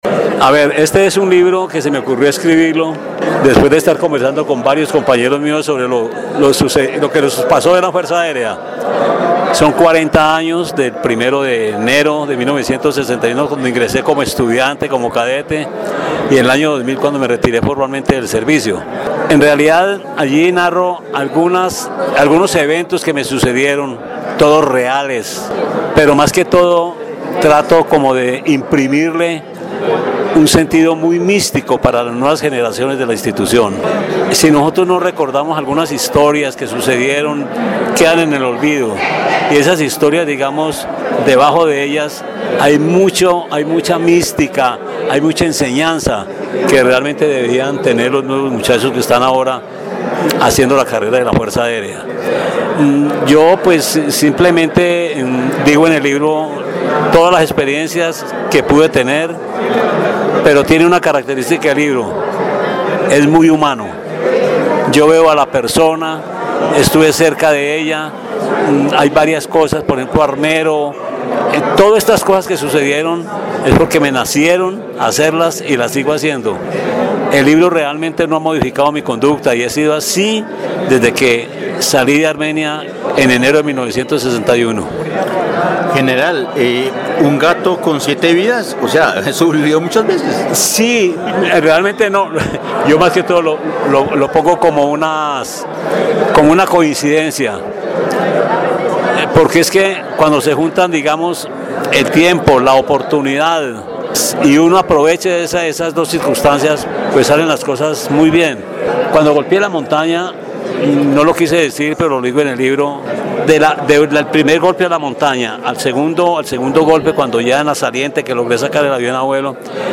En Caracol Radio Armenia hablamos con el General ® Fabio Zapata Vargas oriundo de Armenia y que contó detalles de lo que plasmo en este libro luego de 40 años de servicio en la Fuerza Aérea Colombiana.